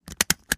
fo_stapler_05_hpx
Papers are stapled together. Paper, Staple